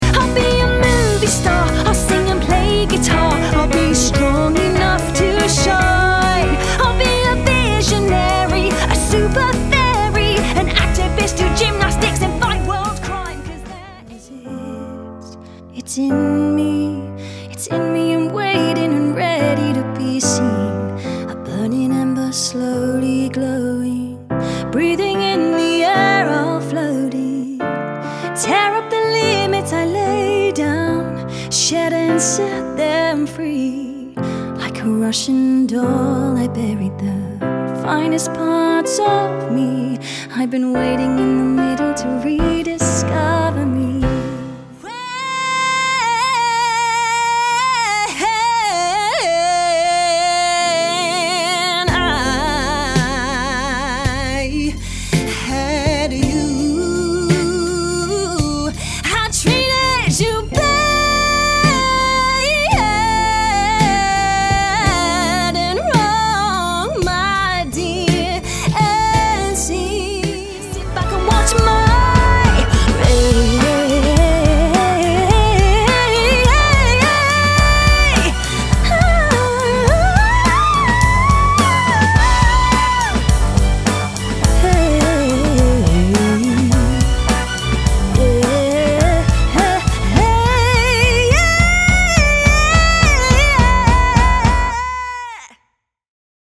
Singing Showreel
Female